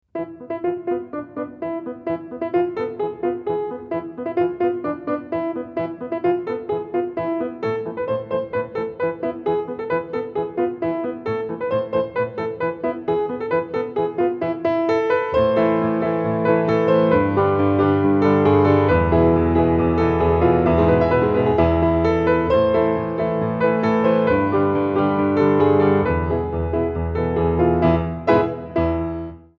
Instrumental (236)